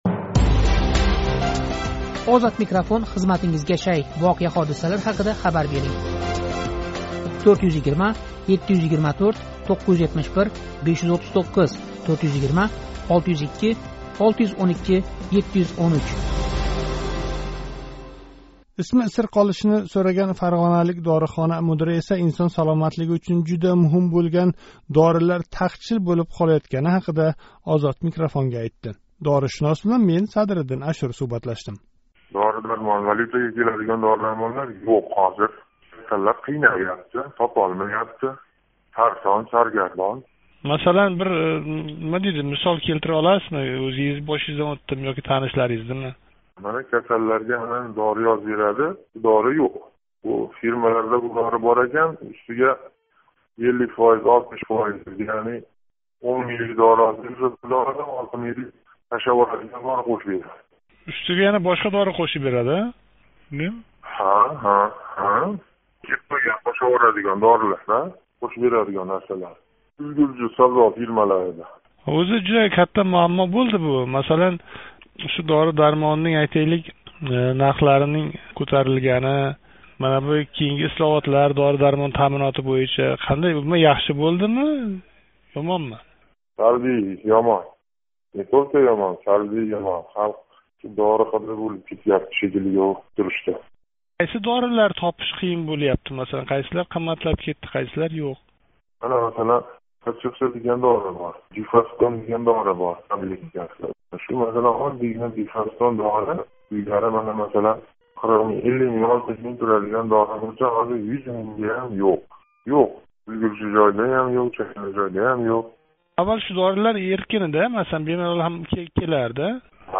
Фарғоналик дорихона мудири билан суҳбат